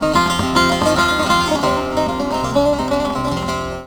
SAZ 07.AIF.wav